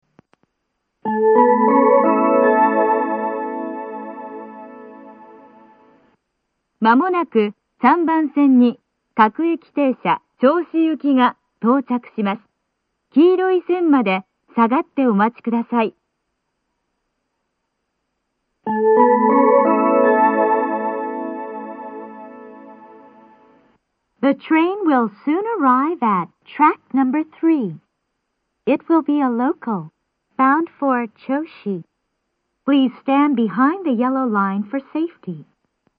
この駅は禁煙放送が流れていて、それが被りやすいです。
３番線接近放送 各駅停車銚子行の放送です。